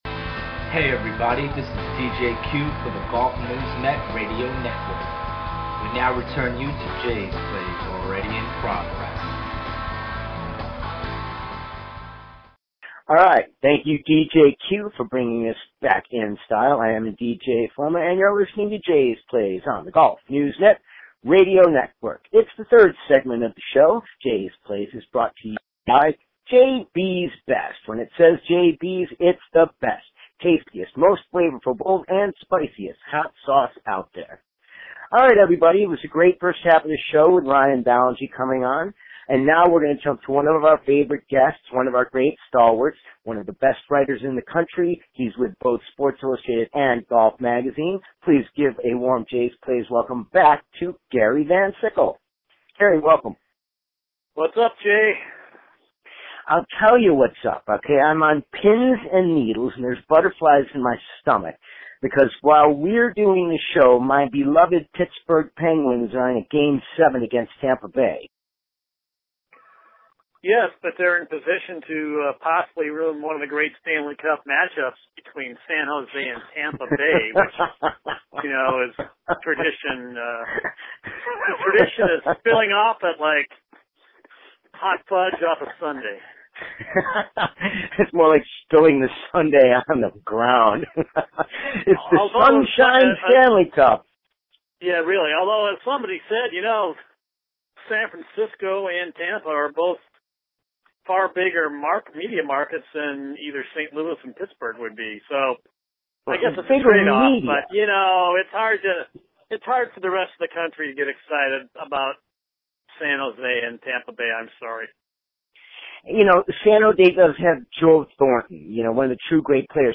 a two-part conversation covering a host of topics in the world of golf, including getting ready for the 2016 U.S. Open at Oakmont.